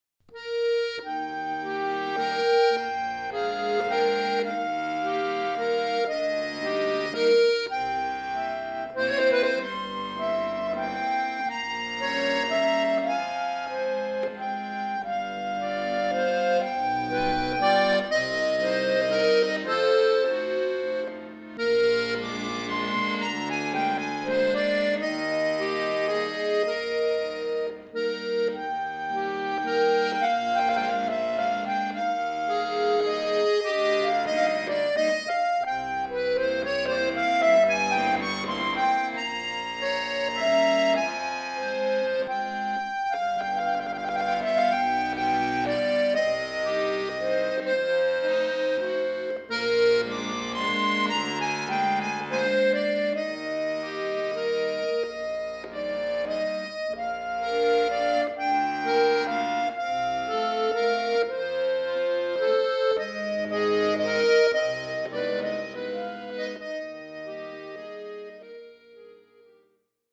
Romantischer Walzer
neu arrangiert für Akkordeon solo